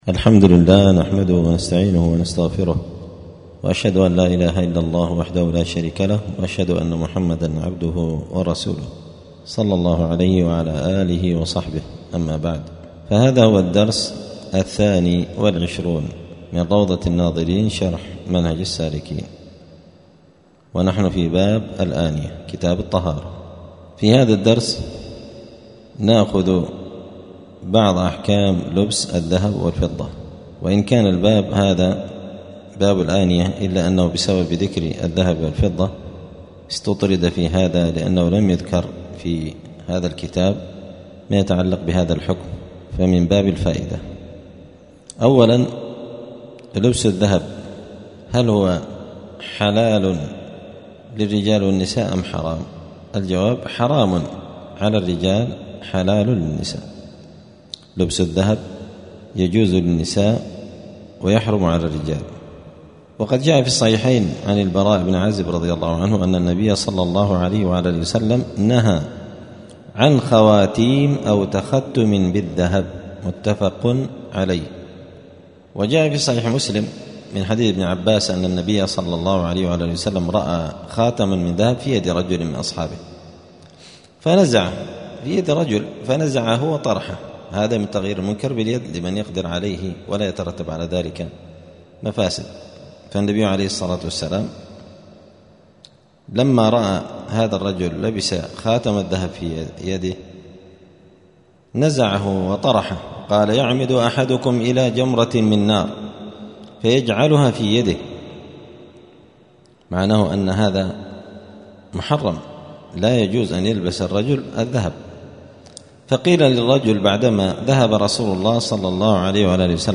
*الدرس الثاني والعشرون (22) {كتاب الطهارة فصل المياه باب الآنية أحكام لبس الذهب أو الفضة}*